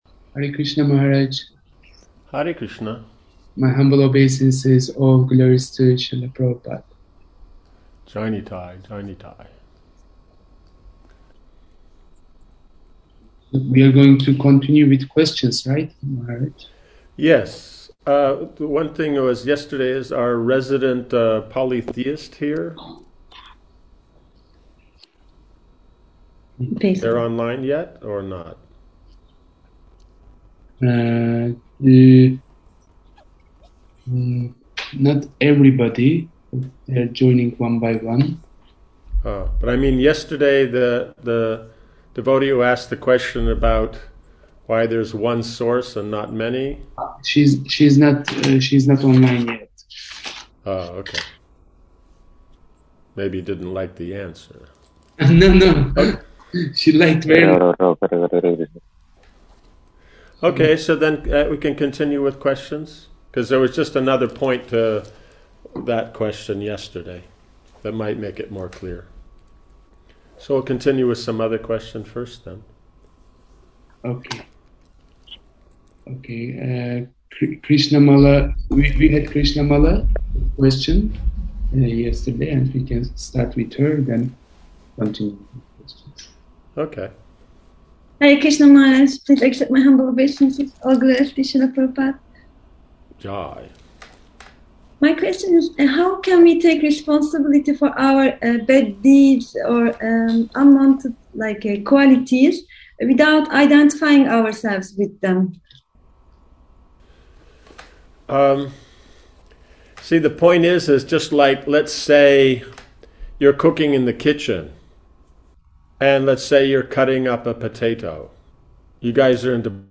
Lust, Anger and Greed Seminar